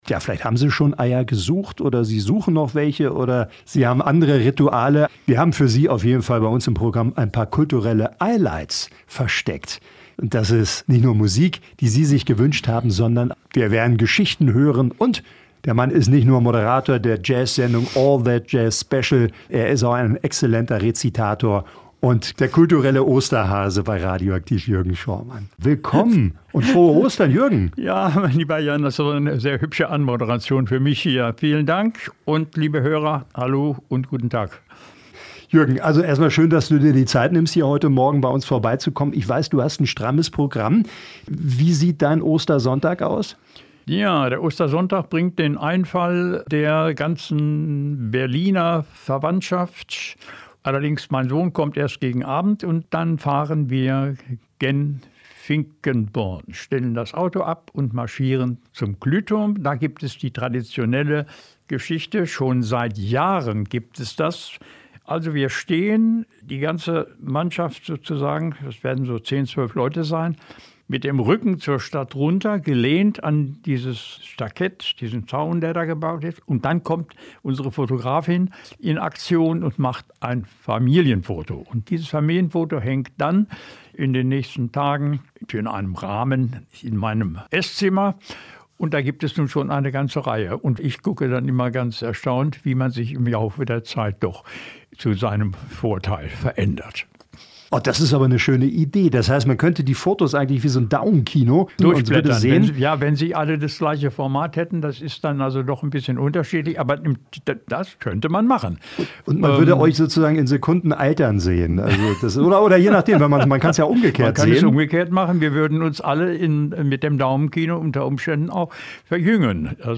liest Gedichte zum Osterfest